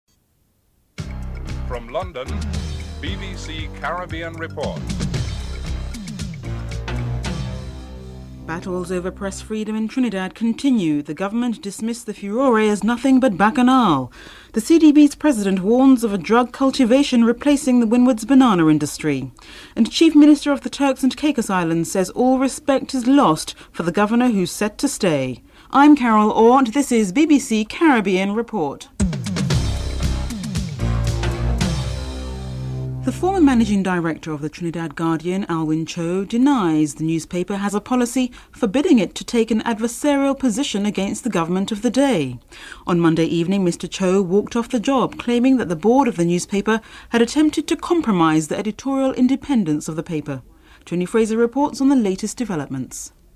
1. Headlilnes (00:00-00:31)
Senator Wade Mark is interviewed (02:07-05:20)
Chief Minister Reuben Meade is interviewed (10:43-11:39)
Chief Minister Derek Taylor is interviewed (11:40-13:24)